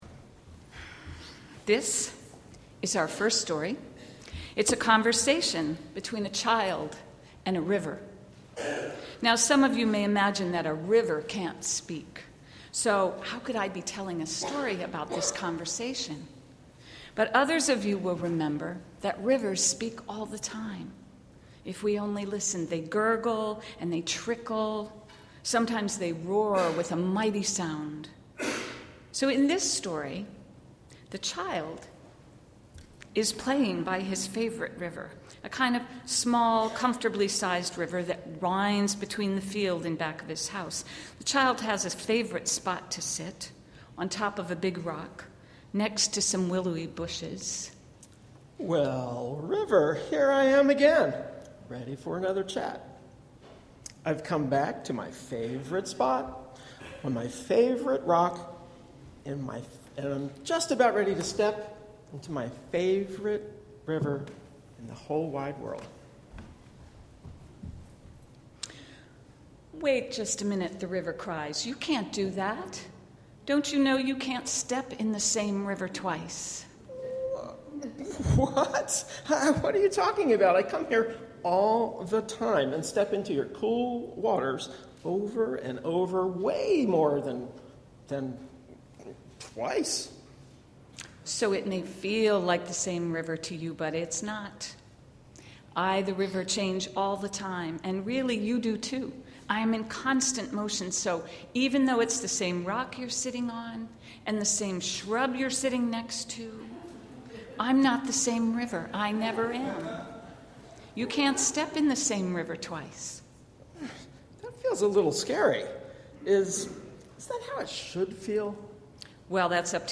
We celebrate spring and renewal with a multigenerational service drawing from stories marking traditional Christian and Jewish holidays and accompanied by American jazz.